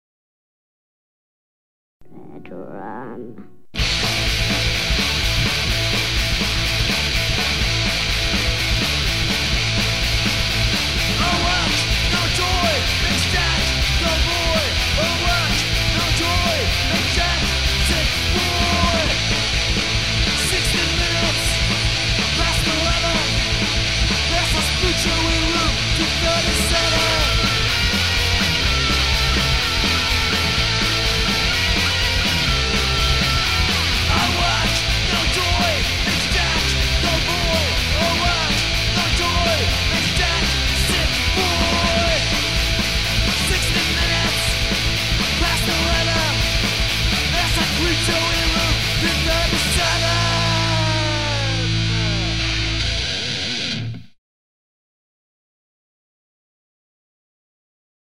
destructo-punks
played with a reckless trashy abandon
Guitar & Bass
Drums & Tambourines